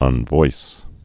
(ŭn-vois)